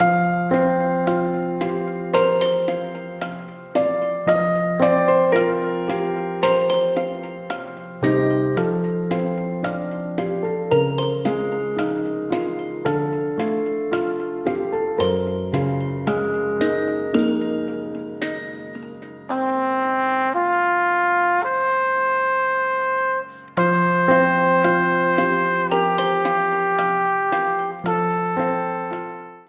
Ripped from the game
faded out the last two seconds